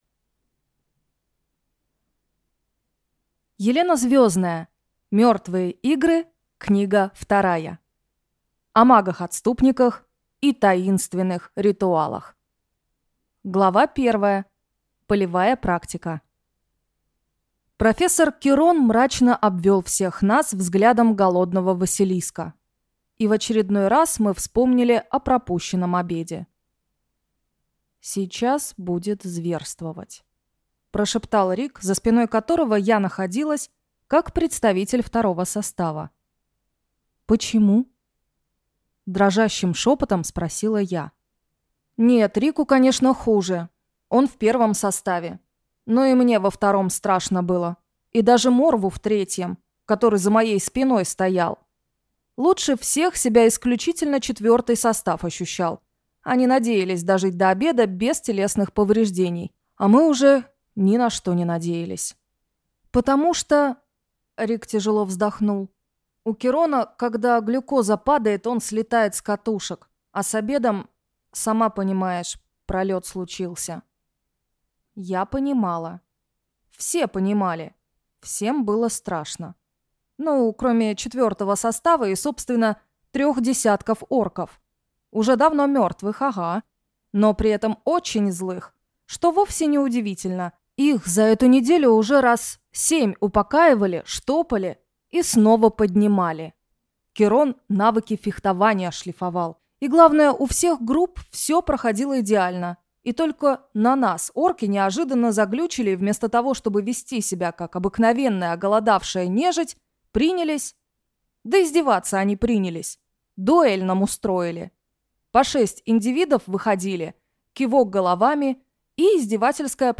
Аудиокнига Мертвые игры. Книга вторая. О магах-отступниках и таинственных ритуалах | Библиотека аудиокниг